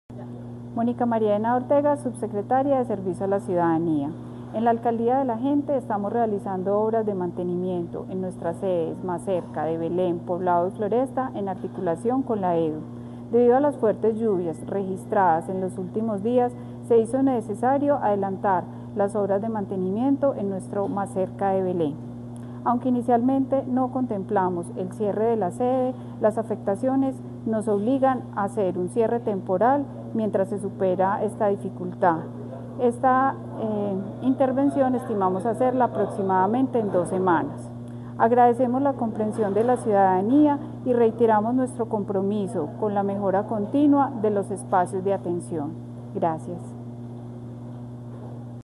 Declaraciones subsecretaria de Servicio a la Ciudadanía, Mónica María Henao
Declaraciones-subsecretaria-de-Servicio-a-la-Ciudadania-Monica-Maria-Henao.mp3